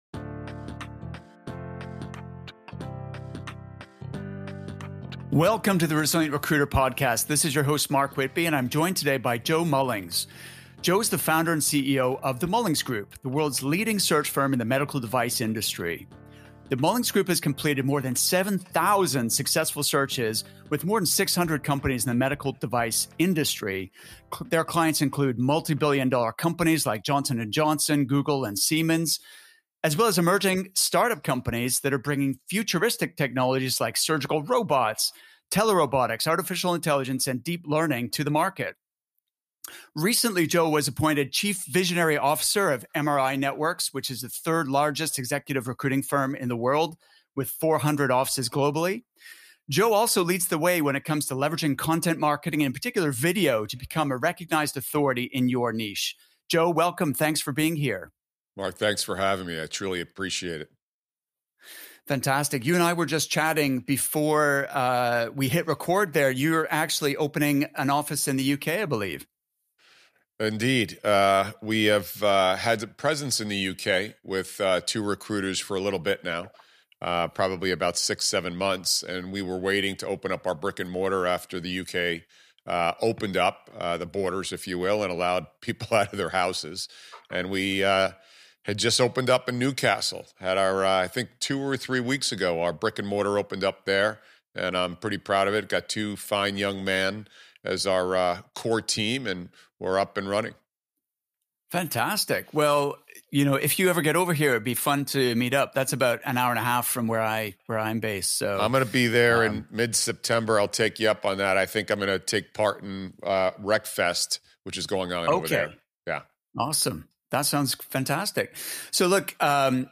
By leveraging digital media and the power of storytelling, you can dominate your market and create a competitive advantage for your clients and your own firm simultaneously. In this interview